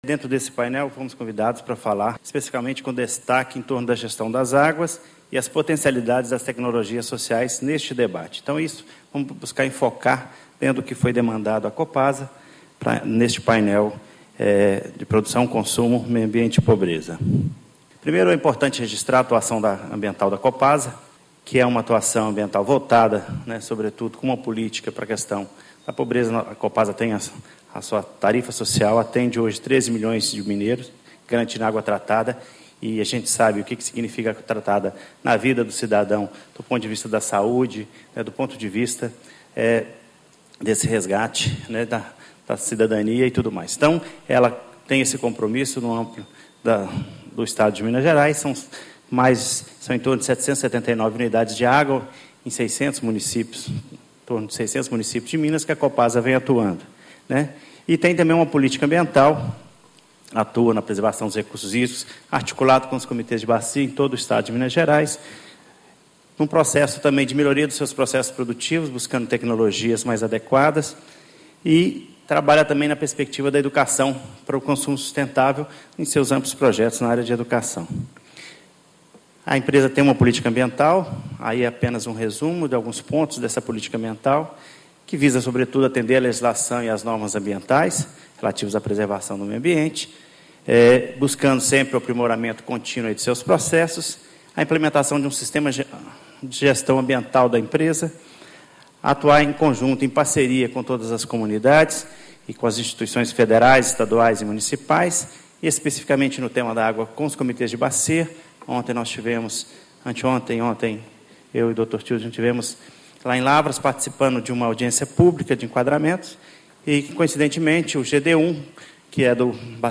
Discursos e Palestras Rumo à Rio + 20 e à Cúpula dos Povos